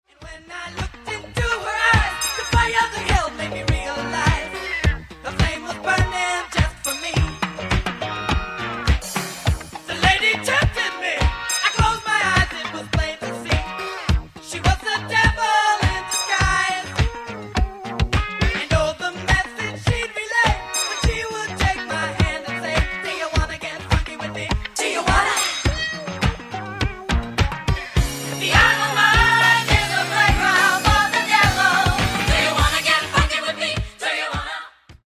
Genere:   Disco Funk
12''Mix Extended